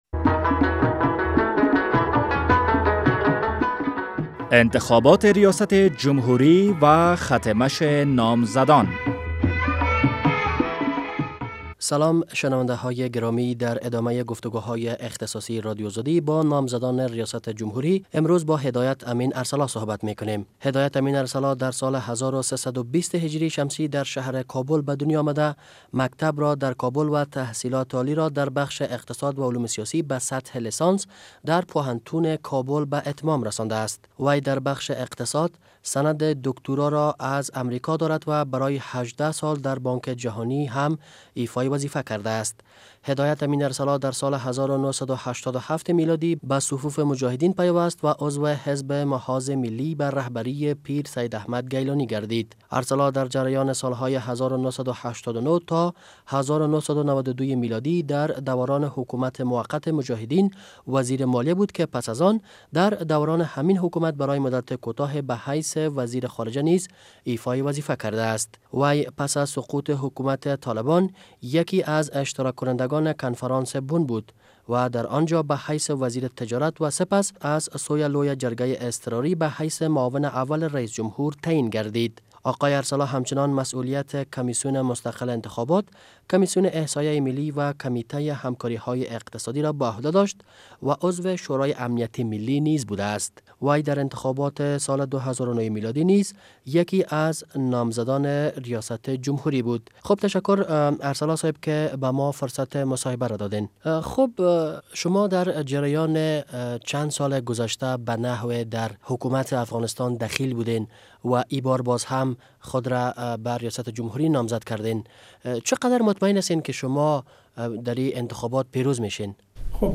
مصاحبهء اختصاصی با هدایت امین ارسلا